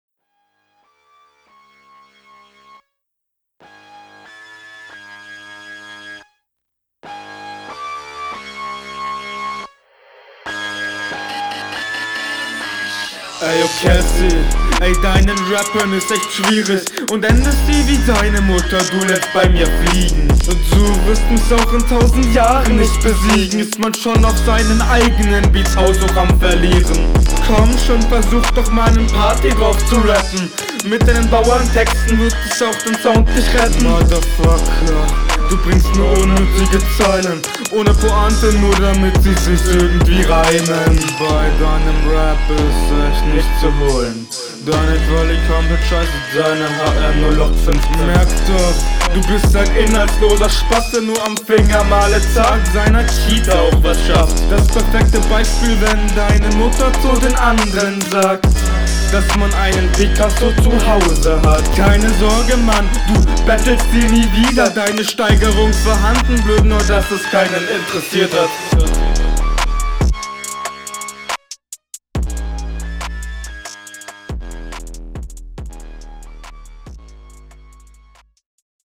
Flow: Nicht so takttreffend.
Nice Beat geht mehr in die Fresse. Dickes Brett!